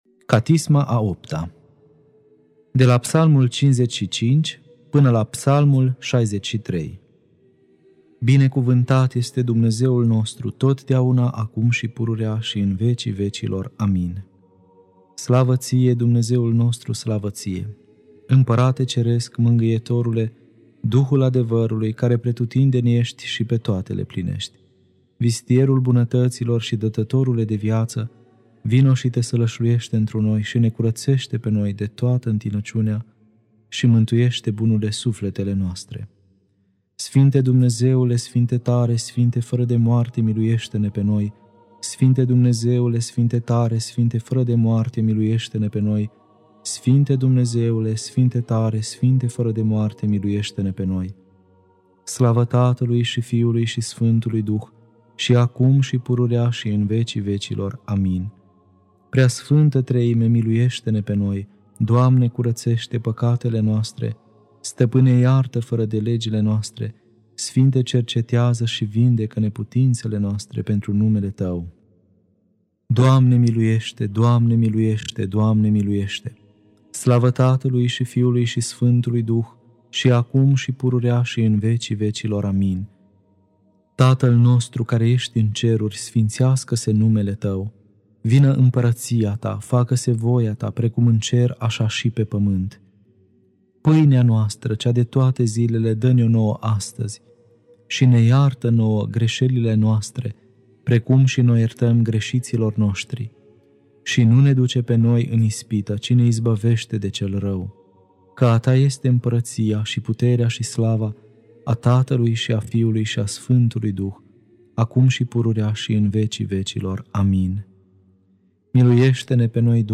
Catisma a VIII-a (Psalmii 55-63) Lectura